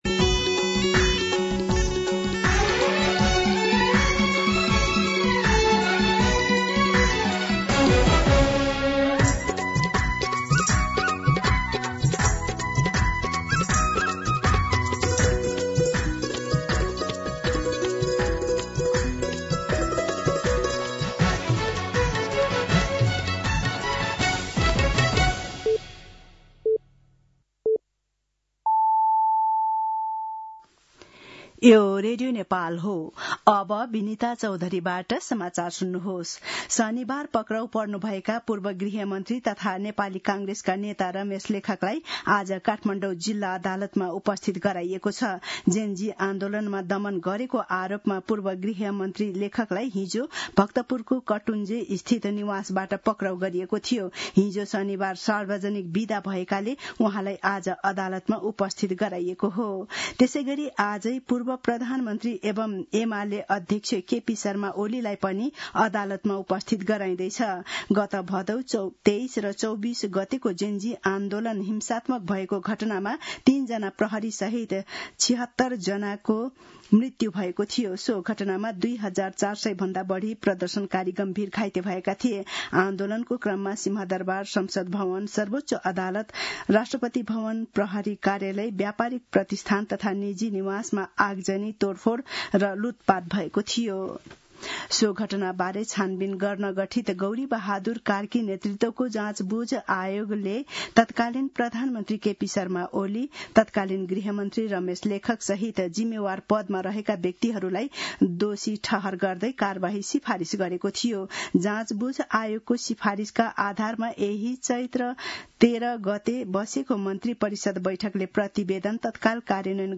मध्यान्ह १२ बजेको नेपाली समाचार : १५ चैत , २०८२